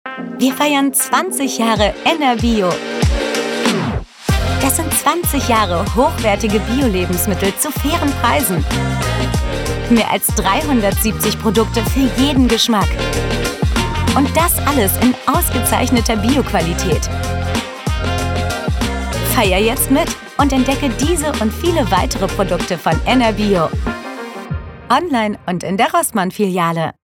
Fiable
Amical
Authentique